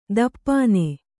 ♪ dappāne